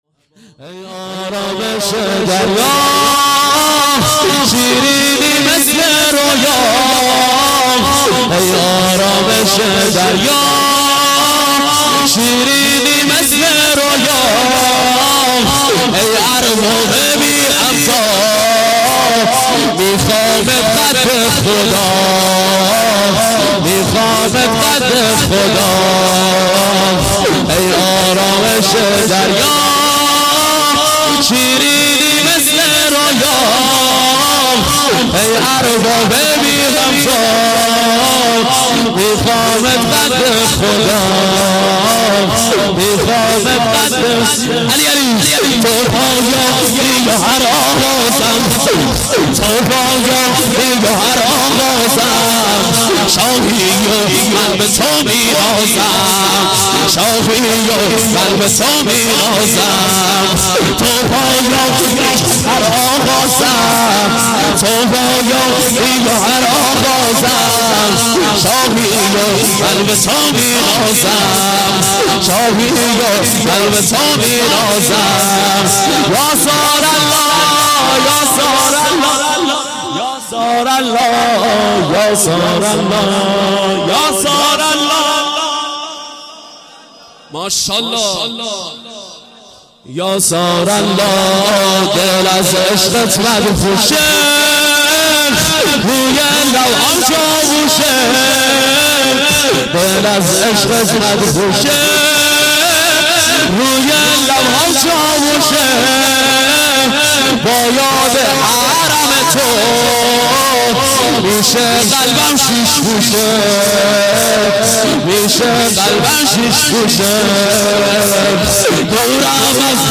شور - ای آرامش دریا شیرینی مثل رویا